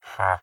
Minecraft Version Minecraft Version 25w18a Latest Release | Latest Snapshot 25w18a / assets / minecraft / sounds / mob / villager / haggle3.ogg Compare With Compare With Latest Release | Latest Snapshot
haggle3.ogg